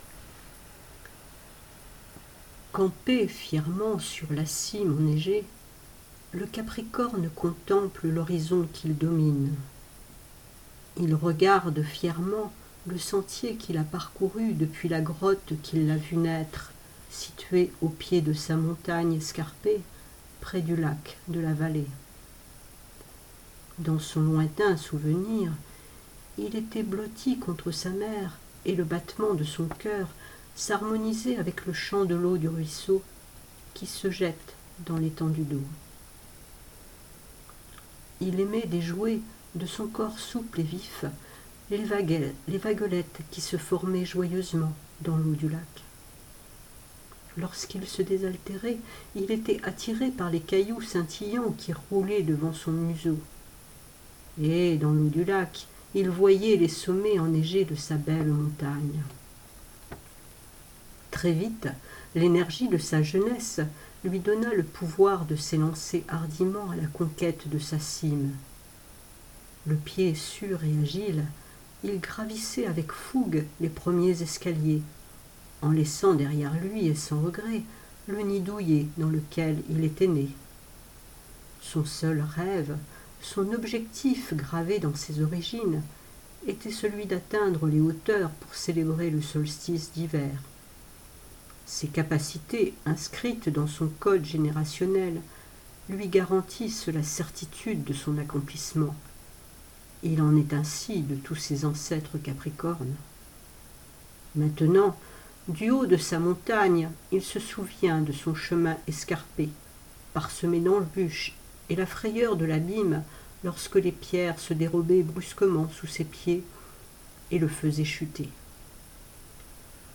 Le rêve - Conte méditatif - Jardins de partage